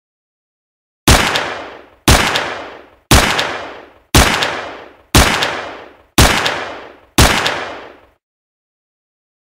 Несколько выстрелов из пистолета Макарова ПМ